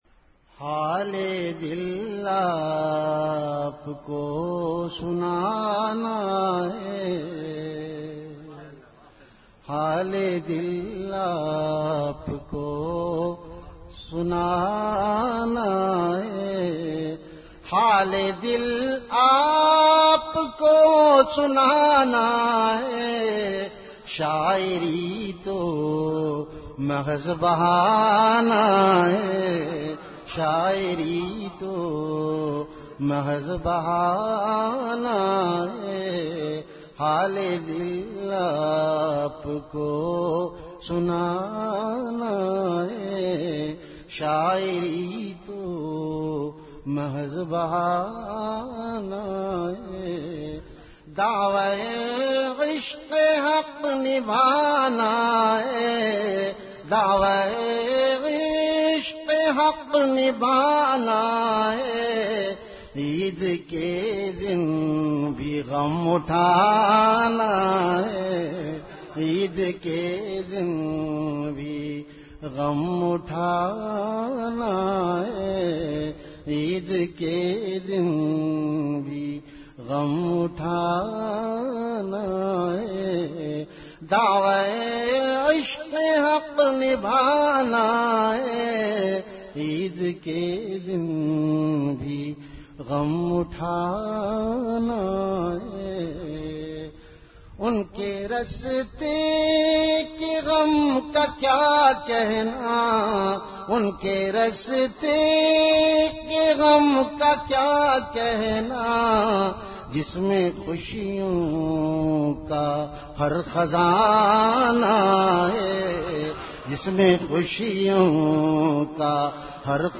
CategoryAshaar
Event / TimeAfter Isha Prayer